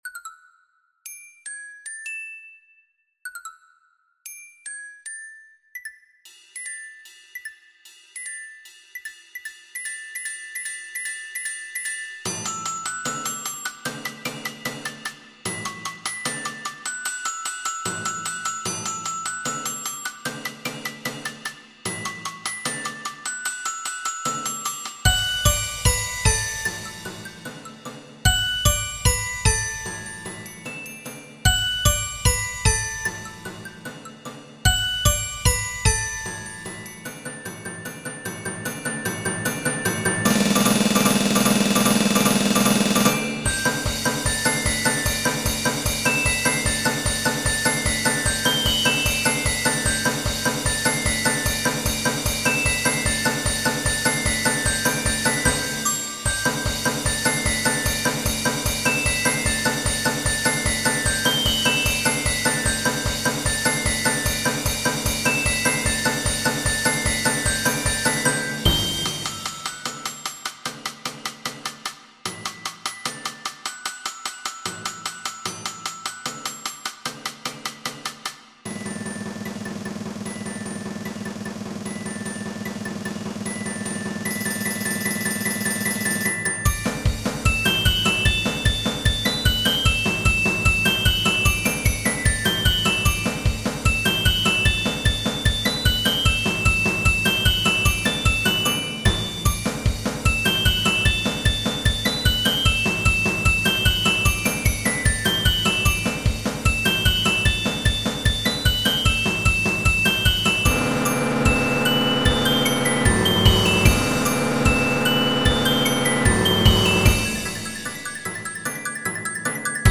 percussion quintet